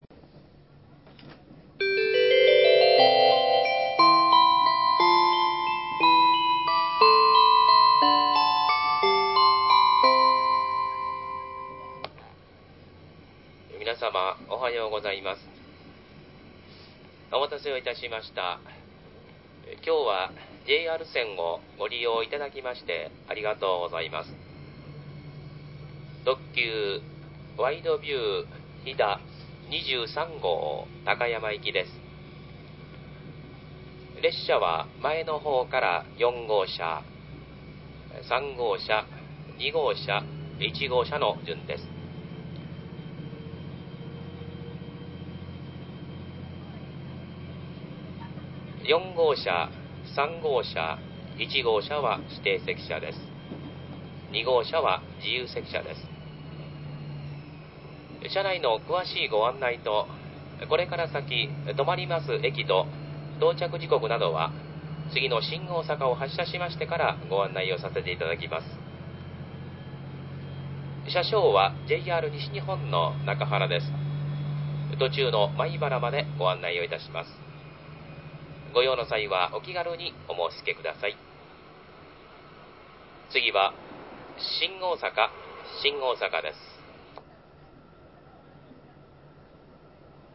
JR東海　車内放送
大阪発車後新大阪発車後   ワイドビューチャイム